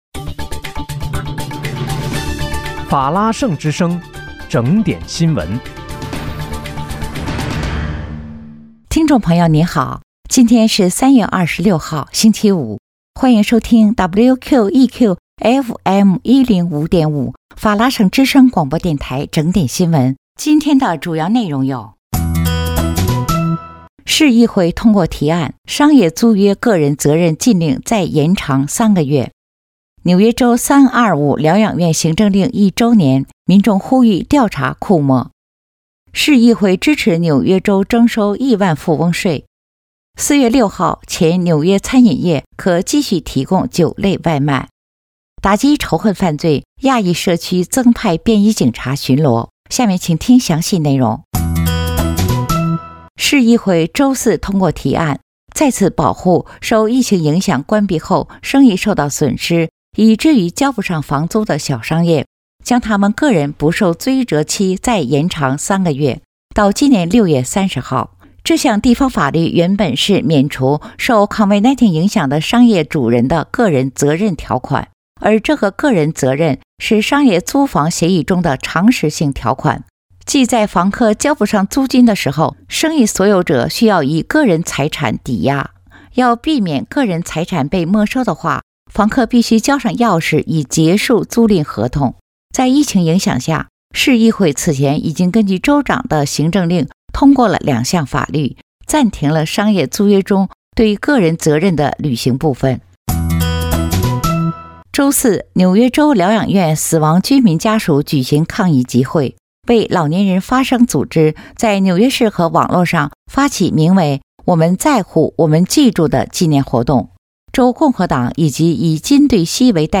3月26日（星期五）纽约整点新闻